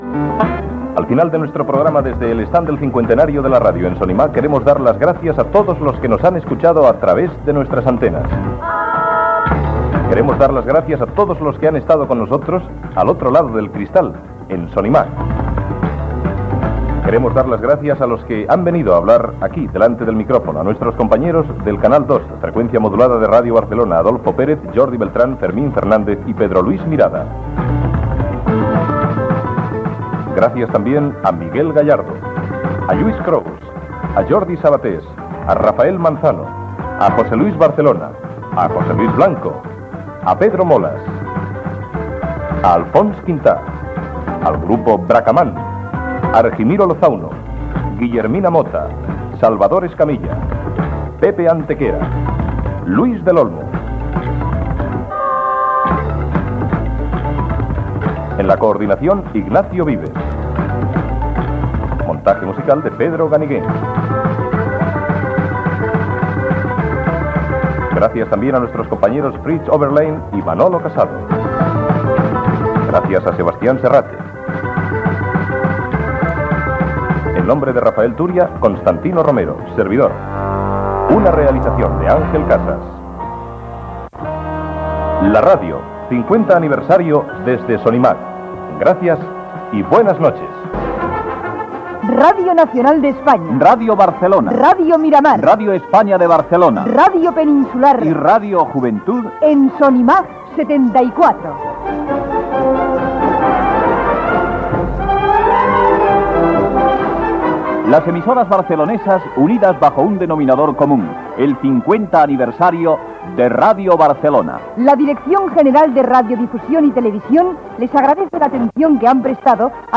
Comiat del programa especial fet des de la Fira Sonimag amb motiu dels 50 anys de Ràdio Barcelona, amb l'agraïment a tot l'equip i invitats que han participat en el programa. Careta de sortida del programa de totes les emissores barcelonines que han participat a Sonimag 1974, hora i publicitat.
Entreteniment